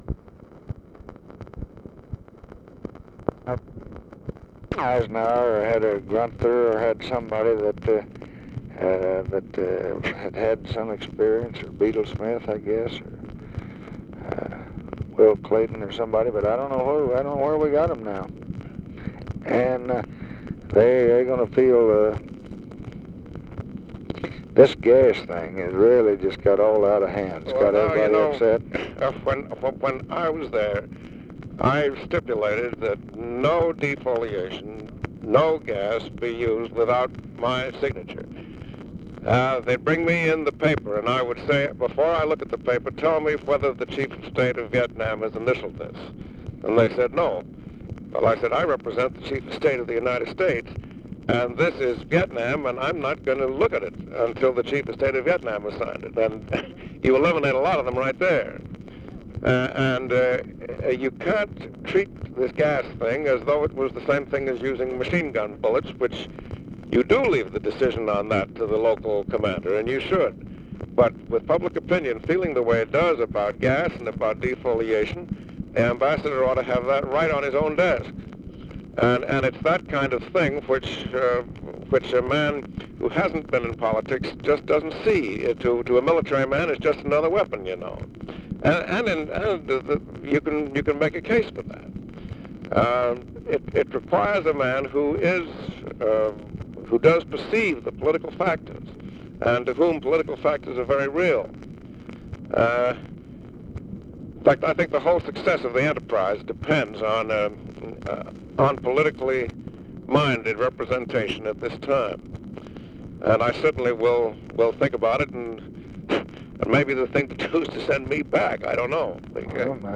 Conversation with HENRY CABOT LODGE, March 24, 1965
Secret White House Tapes